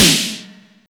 TOM02.wav